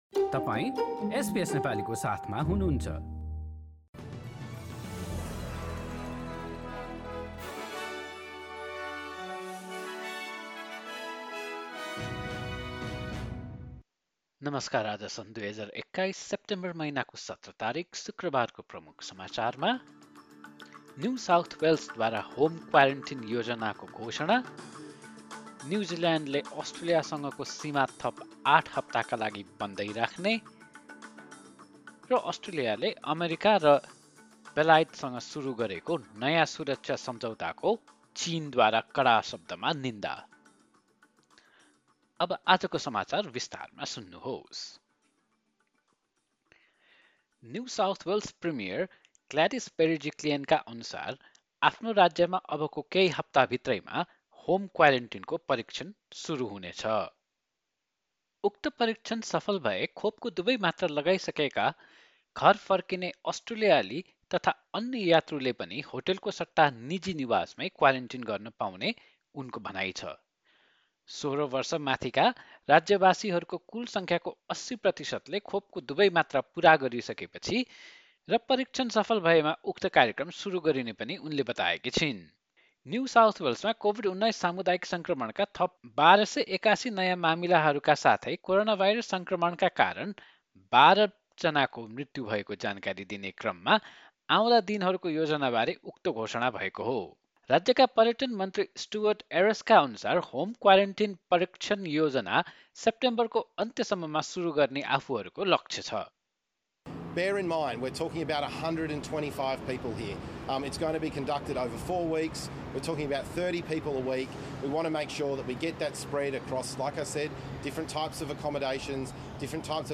एसबीएस नेपाली अस्ट्रेलिया समाचार: शुक्रवार १७ सेप्टेम्बर २०२१